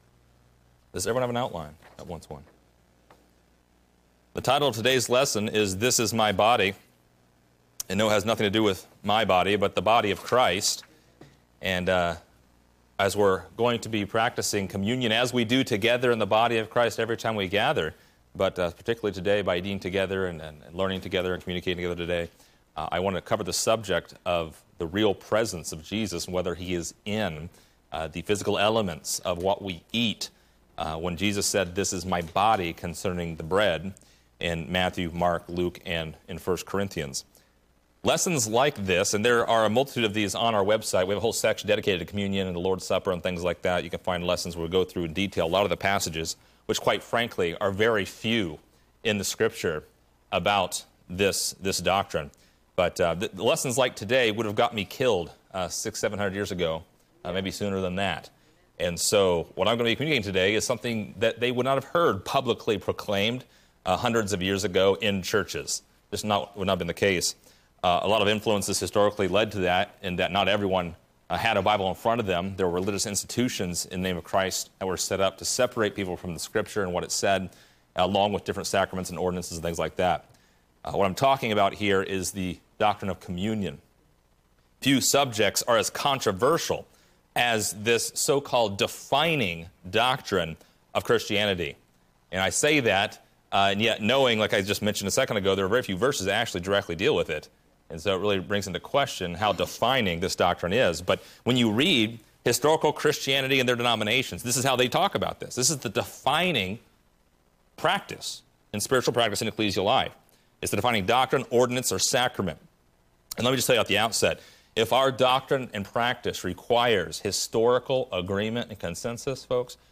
Download MP3 | Download Outline Description: Several times a year, we teach a lesson on Communion.